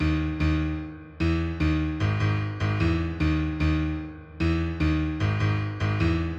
卑微的钢琴循环
Tag: 150 bpm Trap Loops Piano Loops 1.08 MB wav Key : D